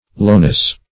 Lowness \Low"ness\, n.